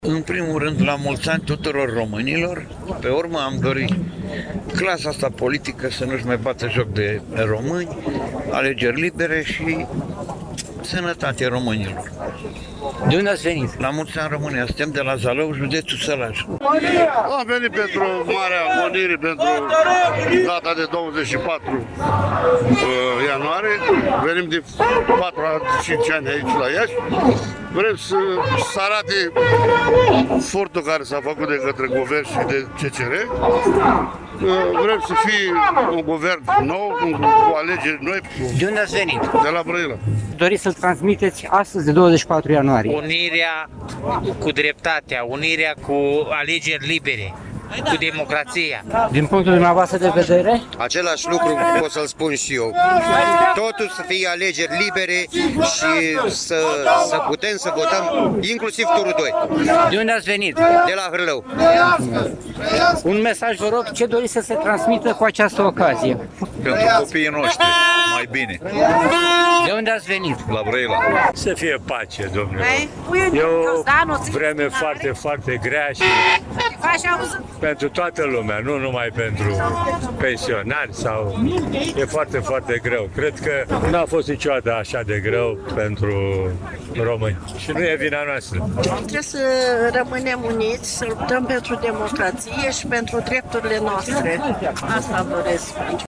Peste 2000 de simpatizanți AUR au protestat astăzi, timp de aproximativ 30 de minute în Piața Unirii din Iași solicitând alegeri libere și democratice.
Aceștia au scandat ”Demnitate”, ”Unitate” și ”Trăiască România”.
24-ian-ora-11-Vox-protest-AUR-1.mp3